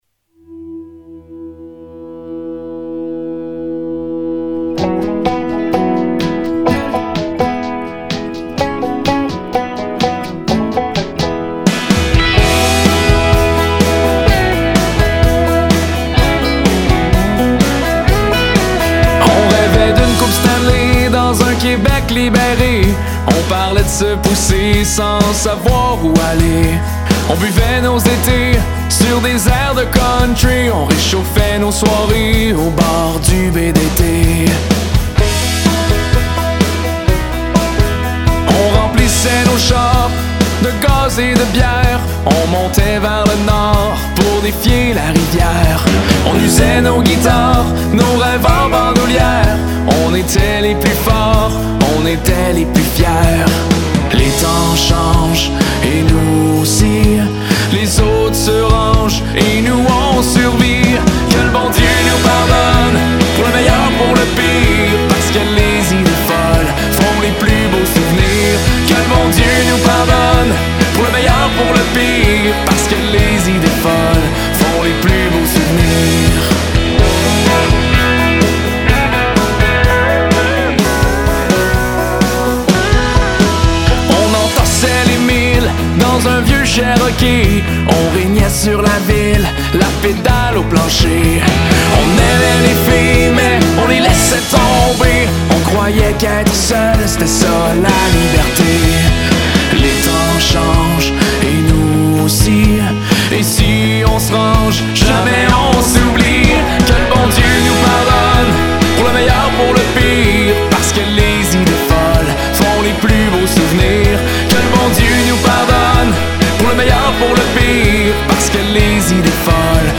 Genre : Country.